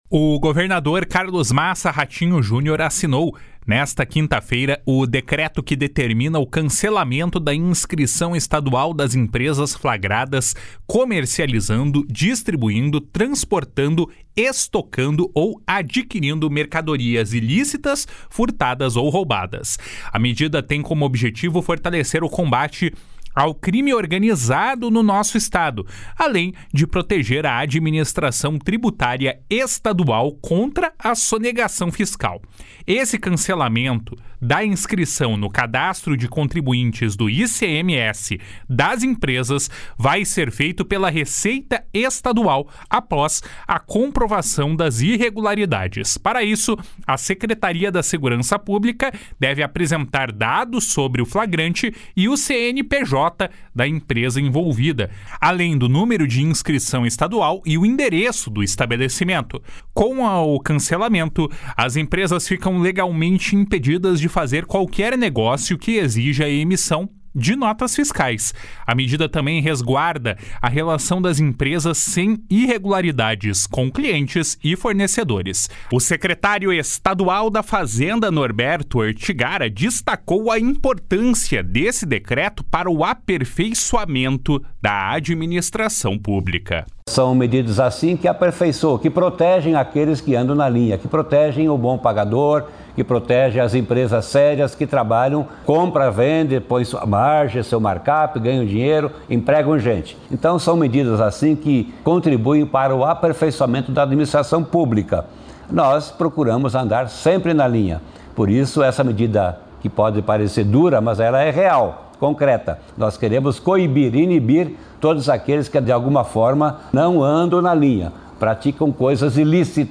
O secretário estadual da Fazenda, Norberto Ortigara, destacou a importância desse decreto para o aperfeiçoamento da administração pública. // SONORA NORBERTO ORTIGARA //